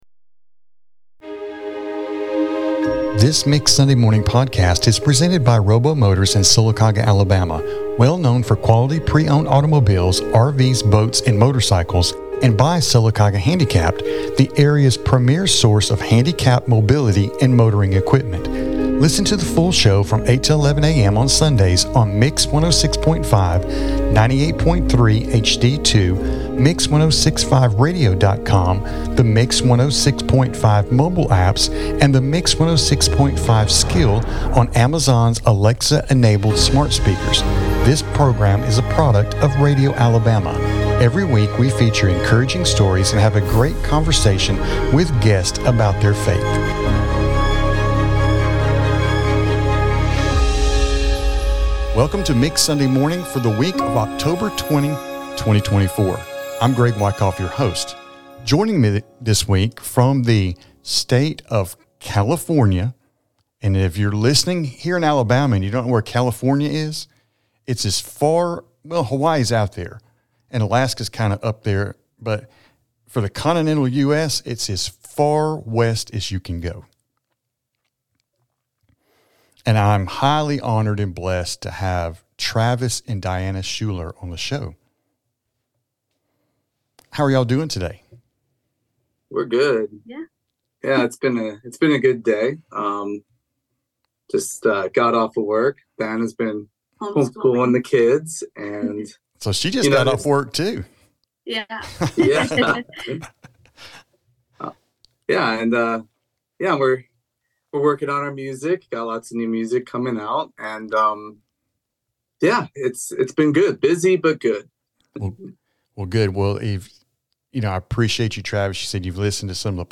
Their conversation was filled with laughter and tears as they shared their powerful testimonies. They talked about how they met and how God is using their children in the music.